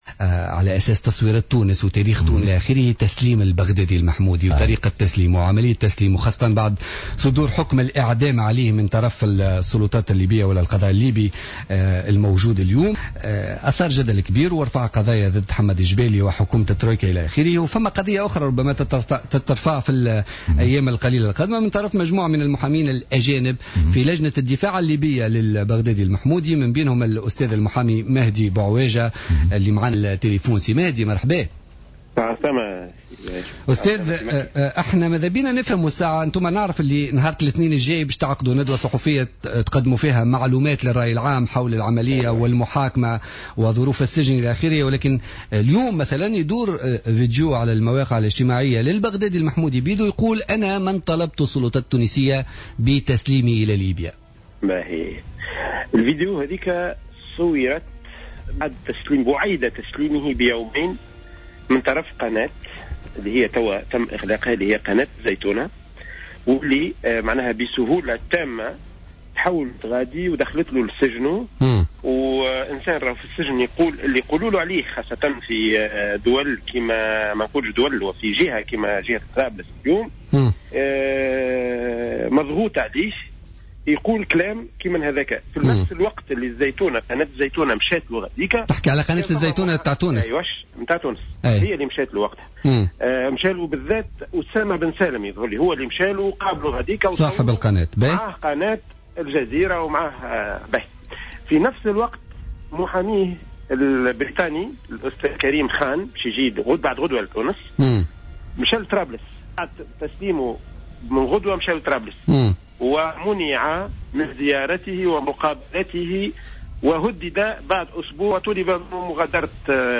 في مداخلة على "جوهرة أف أم" في برنامج "بوليتيكا"